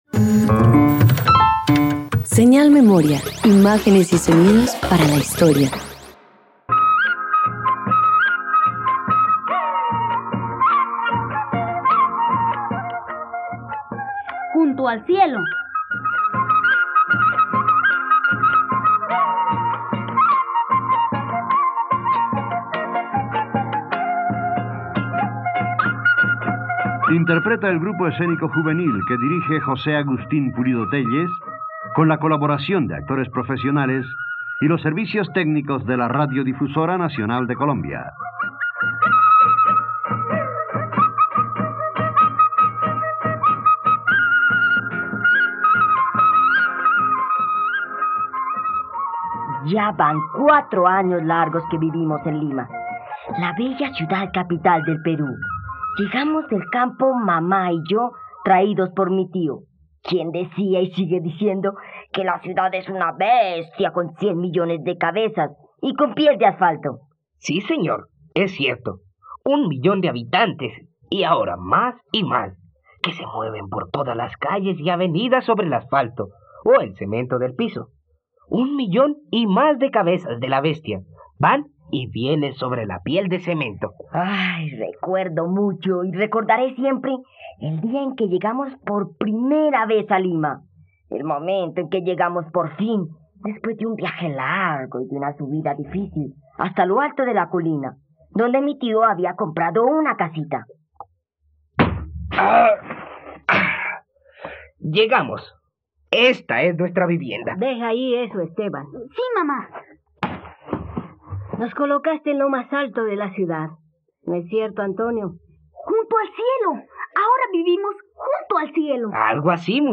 Junto al cielo - Radioteatro dominical | RTVCPlay
..Escucha la adaptación para radio de la obra ‘El niño junto al cielo’ del escritor Enrique Congrains Martín.